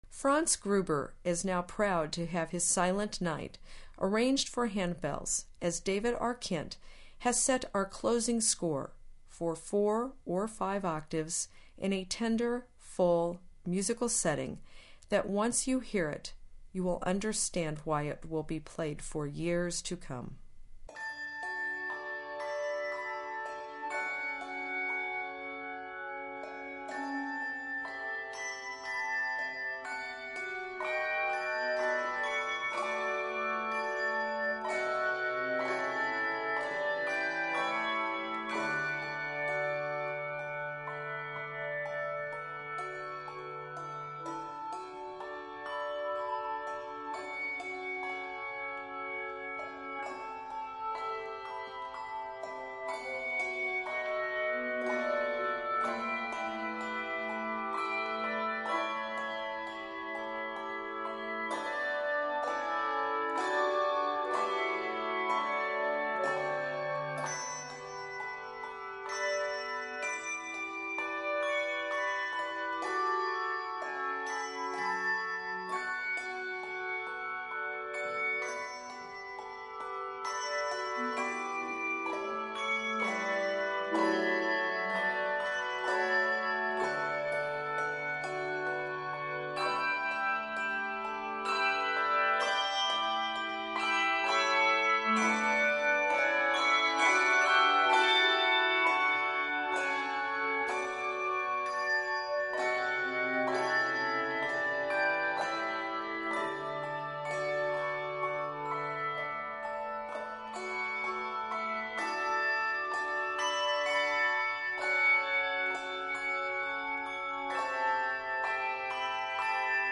Voicing: Handbells 4-5 Octave